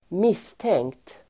Uttal: [²m'is:teng:kt]